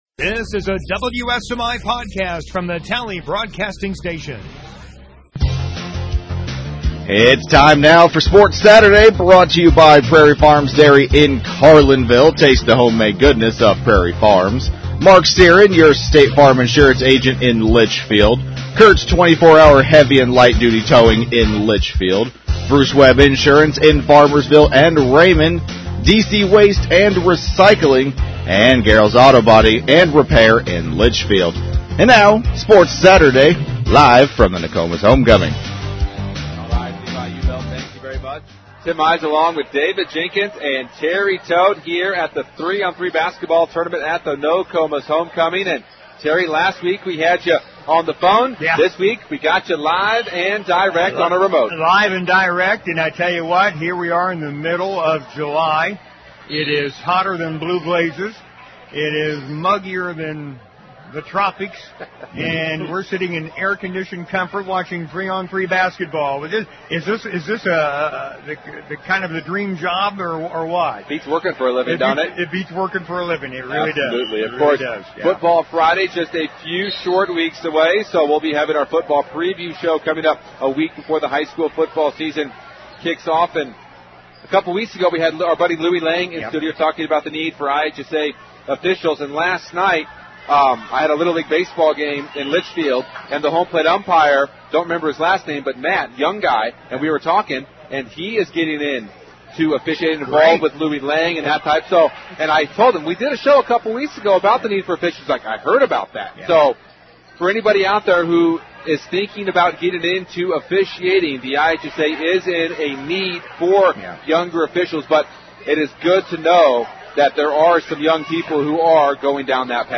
from the 3 on 3 basketball tournament at Nokomis Homecoming